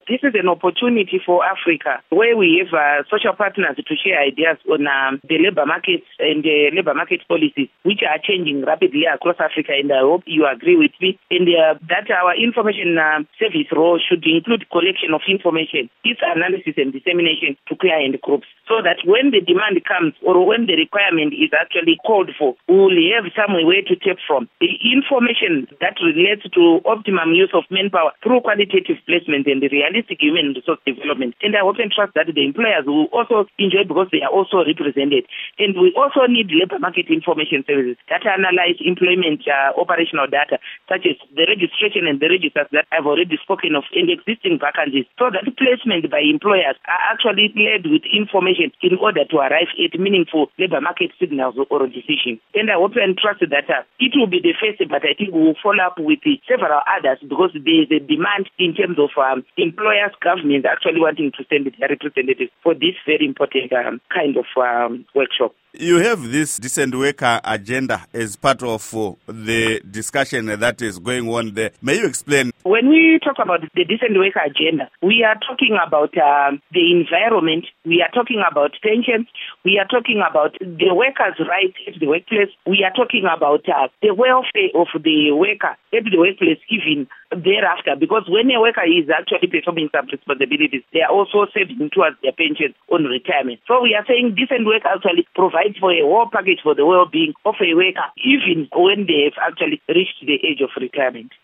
Interview With Paurina Mpariwa Gwanyanya